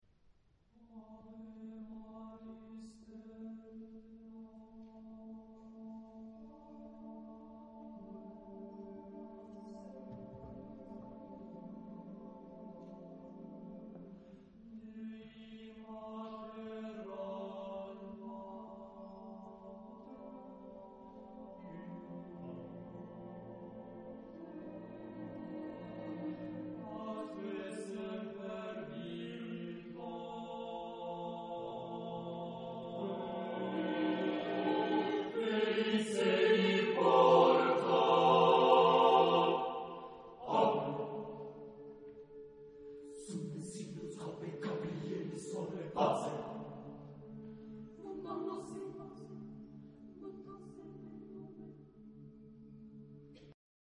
Genre-Style-Forme : Sacré ; Motet ; Chœur ; Hymne (sacré)
Type de choeur : SATB  (4 voix mixtes )
Tonalité : modal